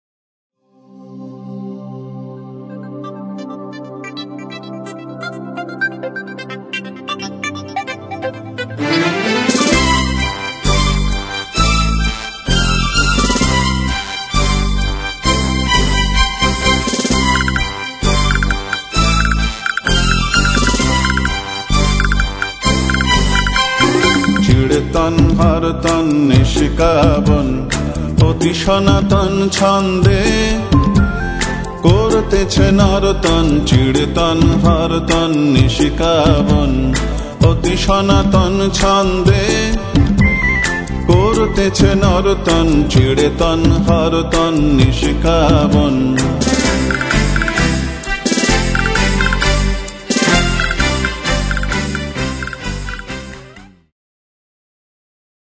Bass, Acoustic and Electric Guitar
Tabla and Percussion